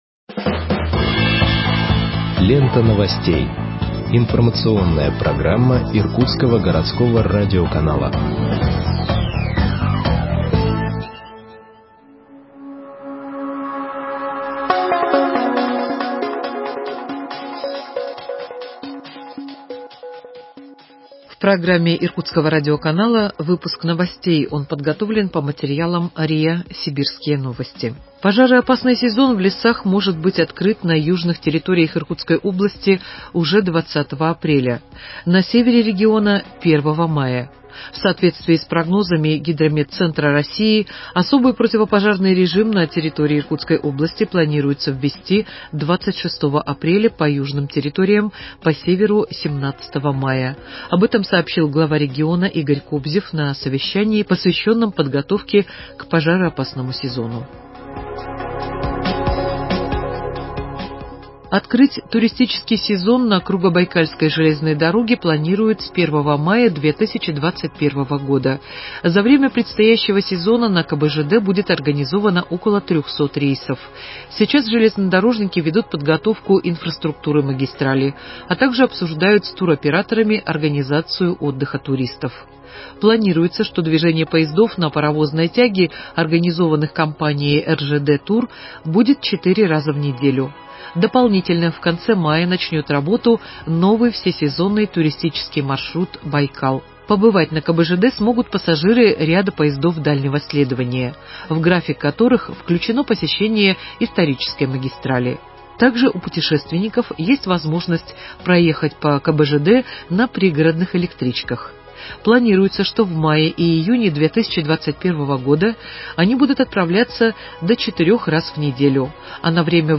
Выпуск новостей в подкастах газеты Иркутск от 14.04.2021 № 2